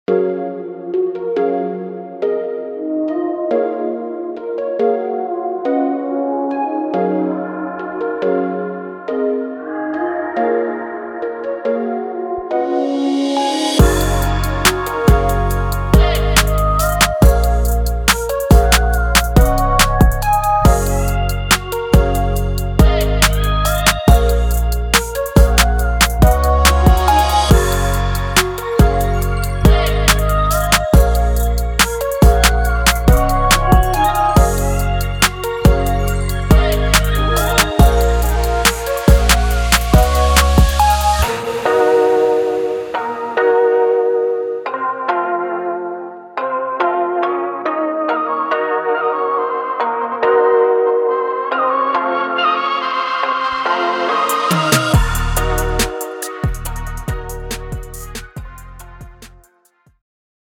Pop sensibilities merge with the sound of modern Trap.